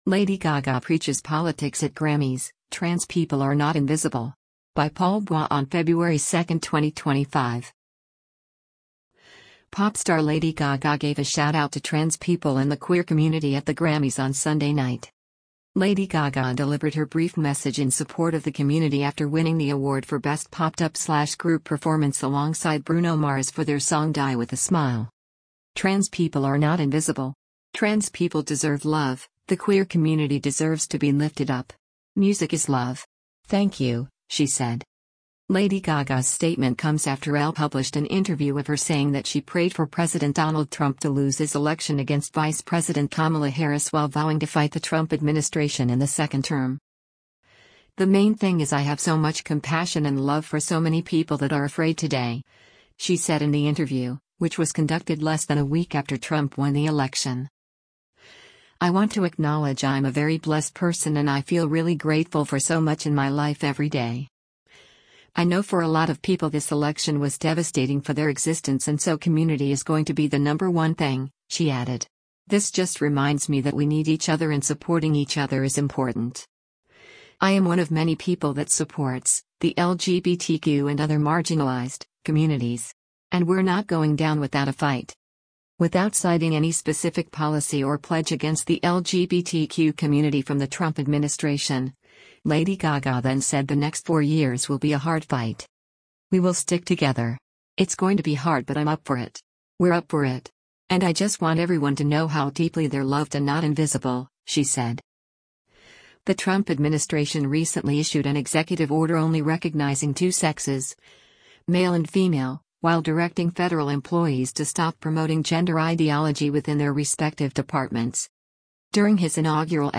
LOS ANGELES, CALIFORNIA - FEBRUARY 02: (L-R) Lady Gaga and Bruno Mars speak onstage during
Pop star Lady Gaga gave a shout out to “trans people” and the “queer community” at the Grammys on Sunday night.